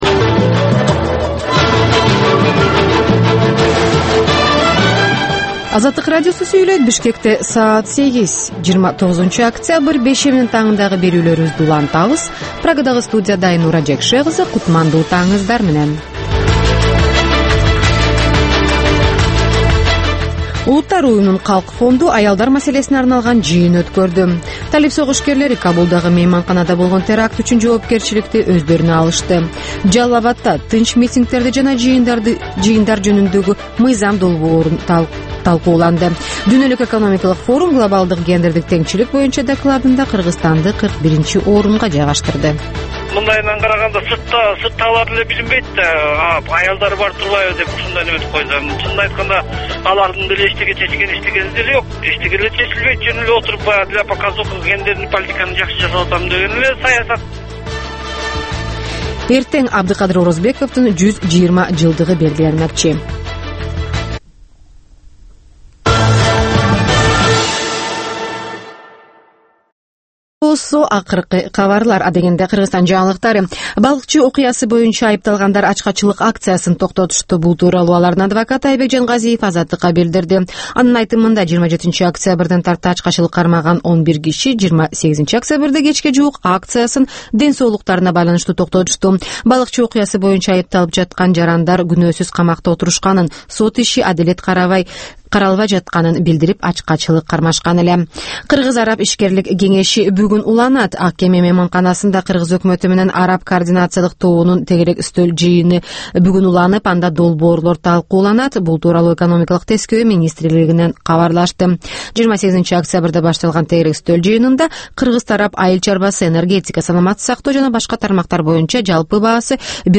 Бул таңкы үналгы берүү жергиликтүү жана эл аралык кабарлардан, ар кыл окуялар тууралуу репортаж, маек, тегерек үстөл баарлашуусу, талкуу, баян жана башка берүүлөрдөн турат. "Азаттык үналгысынын" бул таңкы берүүсү Бишкек убактысы боюнча саат 08:00ден 09:00га чейин обого чыгарылат.